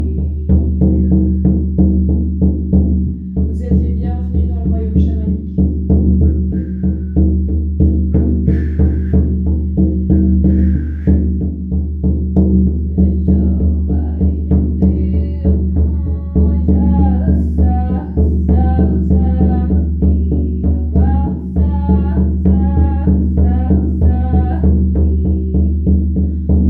Voyage chamanique au tambour – L’hiver: racines de la Terre et maison intérieure (50min)
Pendant cette séance, vous serez guidé(e) pour :